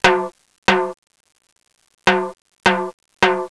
Timbales
Timbales.wav